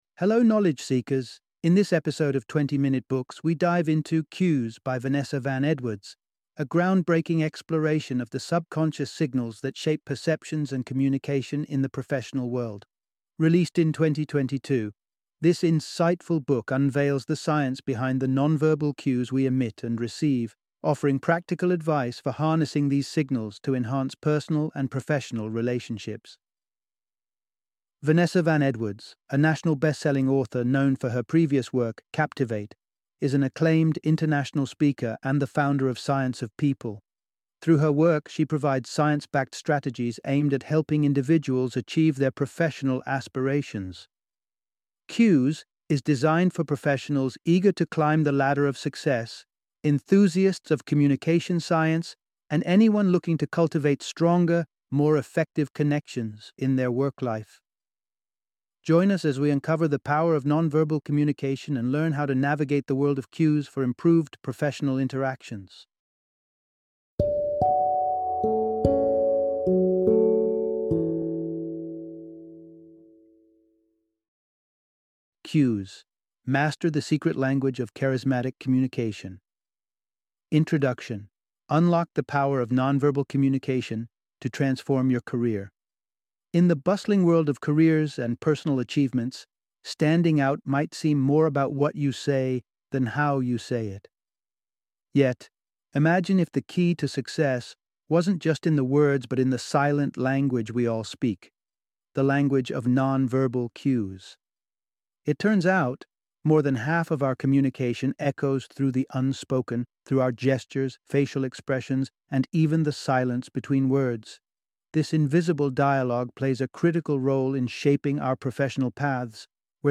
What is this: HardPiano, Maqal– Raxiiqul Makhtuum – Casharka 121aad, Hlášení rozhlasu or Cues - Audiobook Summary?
Cues - Audiobook Summary